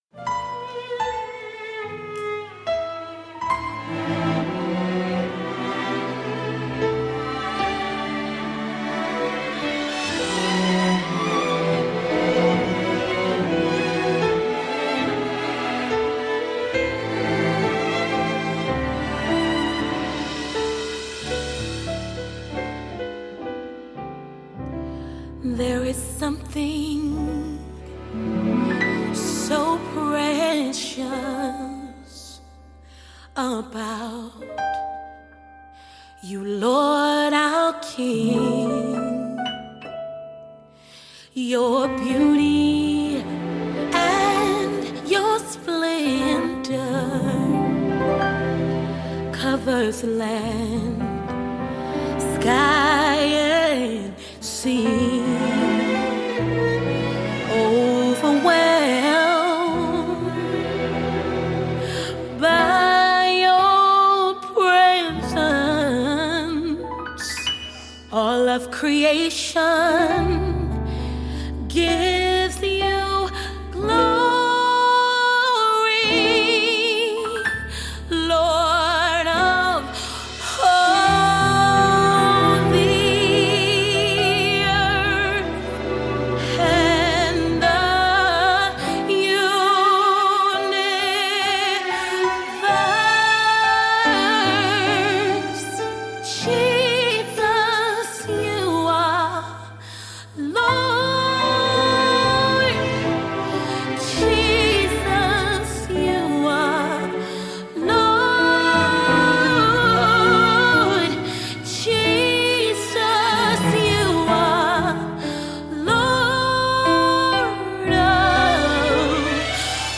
January 24, 2025 Publisher 01 Gospel 0